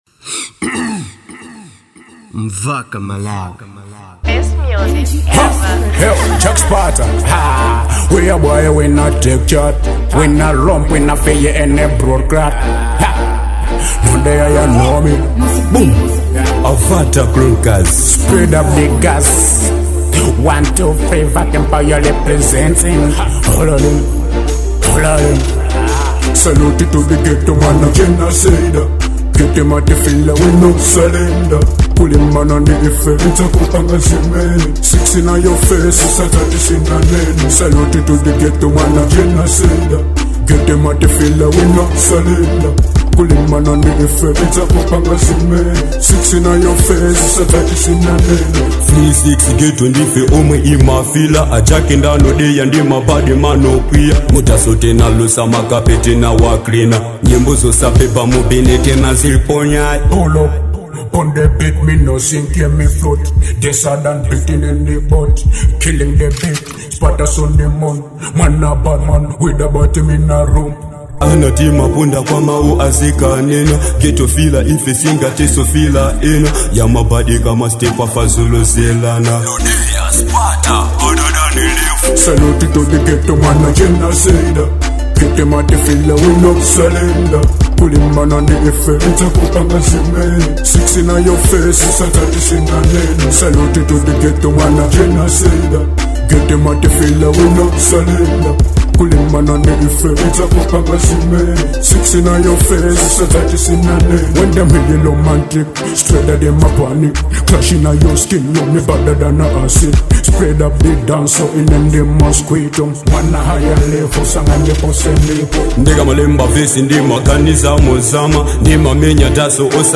type: Dancehall